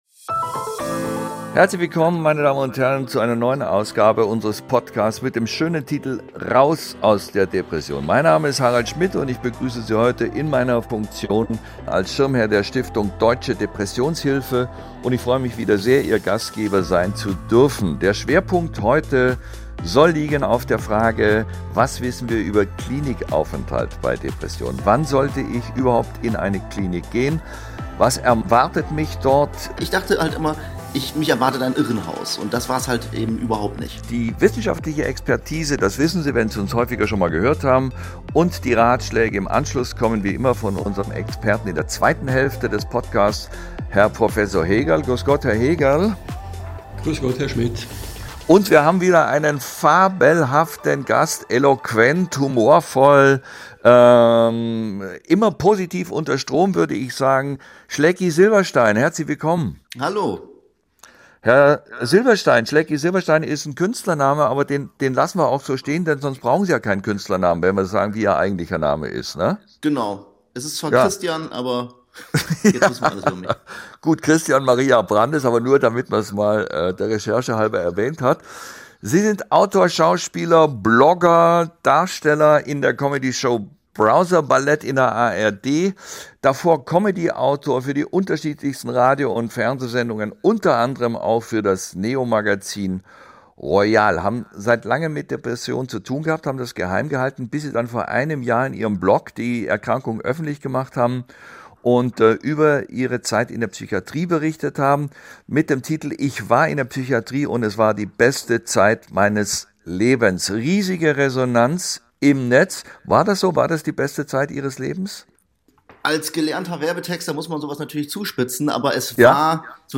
Dabei ist nach der Diagnose einer schweren Depression eine Psychiatrie der beste Ort, um wieder gesund zu werden, sagt unser Gast Christian Maria Brandes alias Schlecky Silberstein. In dieser Podcast-Folge erzählt er Harald Schmidt, warum in der Psychiatrie viel gelacht wird und die Patienten abends Monopoly spielen. Und weshalb man sich die richtige Klinik ganz genau aussuchen sollte.